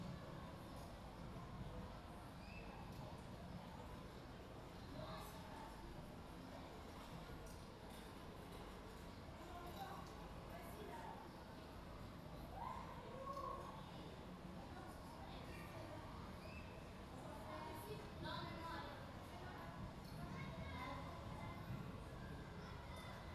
square.wav